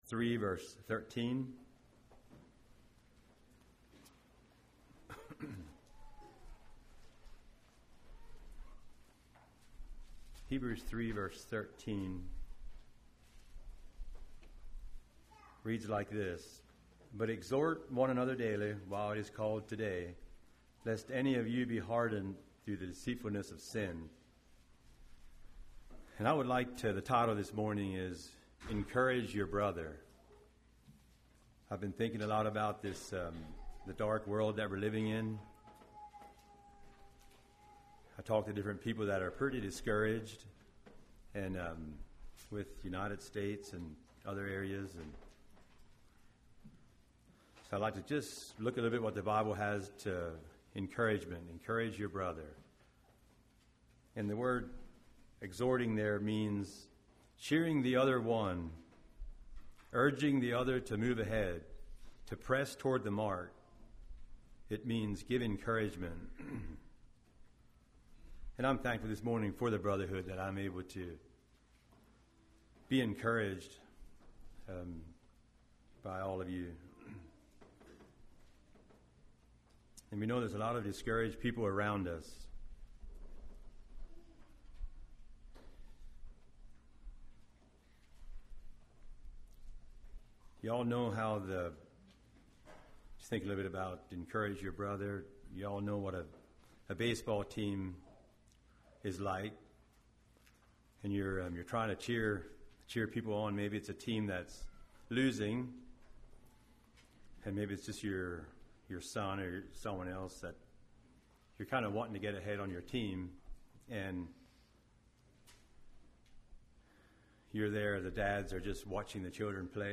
Play Now Download to Device Encourage Your Brother Congregation: Ridge View Speaker